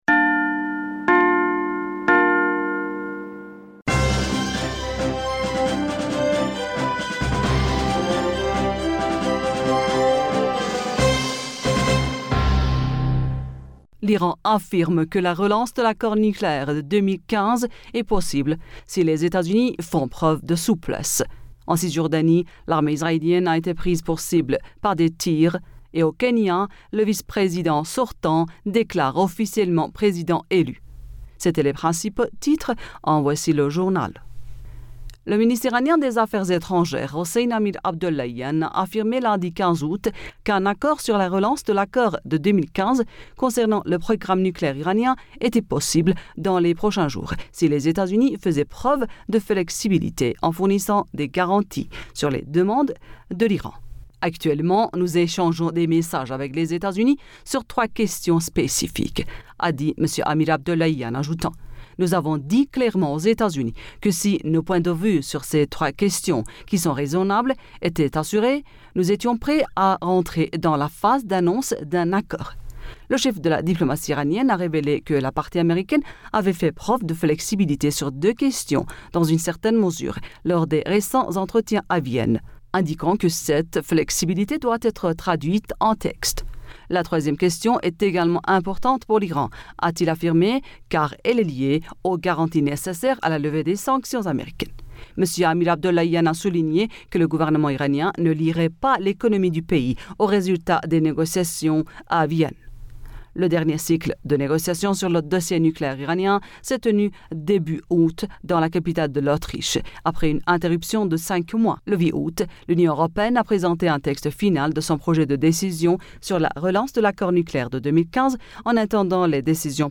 Bulletin d'information Du 16 Aoùt